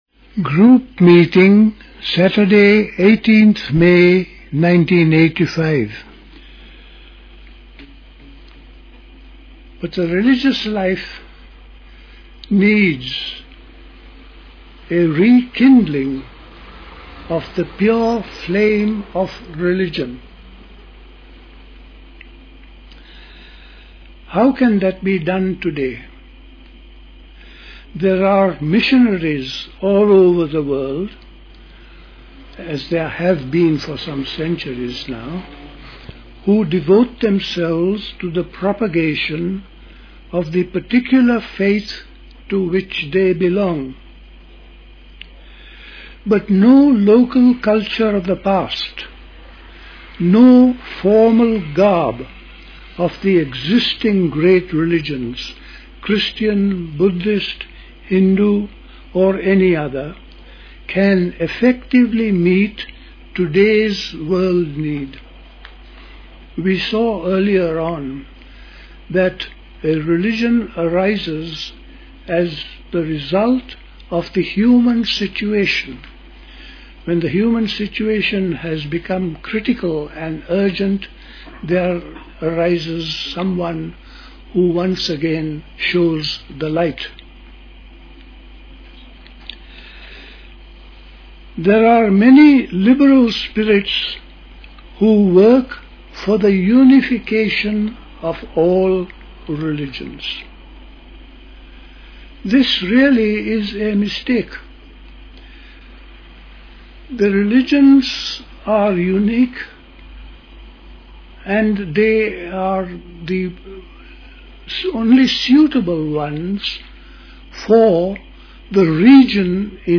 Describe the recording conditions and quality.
at Dilkusha, Forest Hill, London